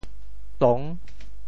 “仲”字用潮州话怎么说？
仲 部首拼音 部首 亻 总笔划 6 部外笔划 4 普通话 zhòng 潮州发音 潮州 dong6 文 中文解释 仲 <形> (会意兼形声。